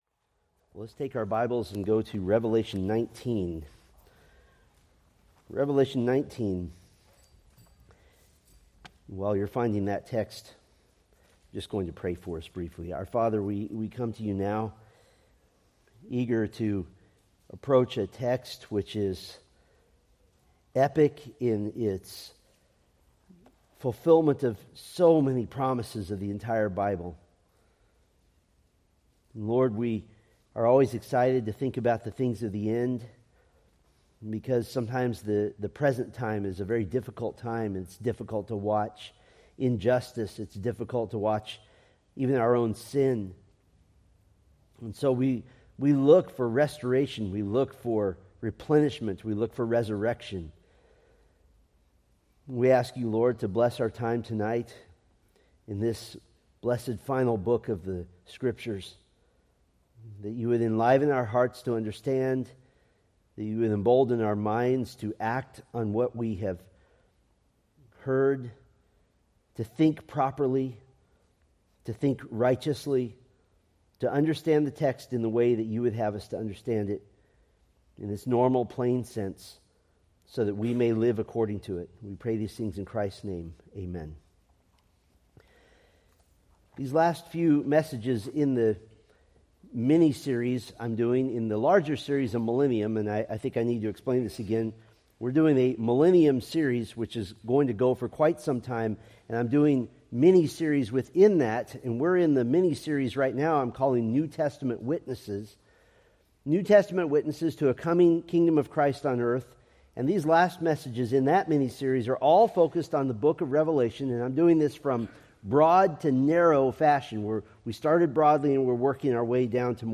From the Millennium: New Testament Witnesses sermon series.